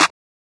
Southside Clap (1).wav